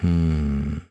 Riheet-Vox_Think.wav